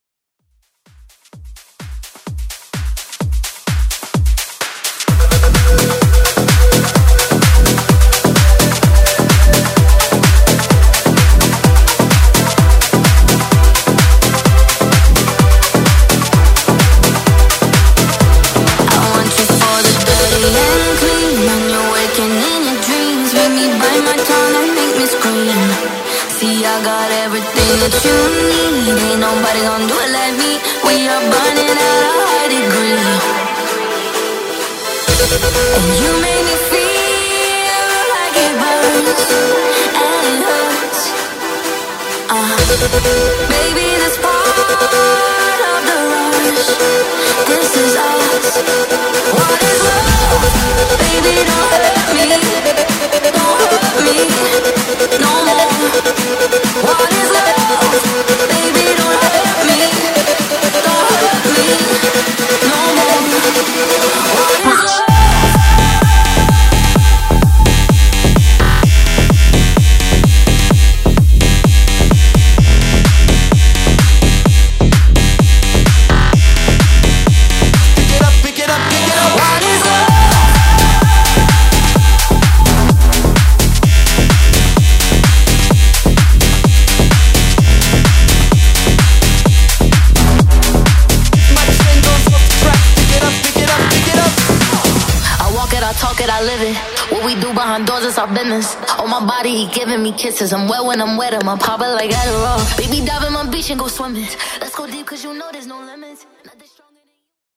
BPM: 128 Time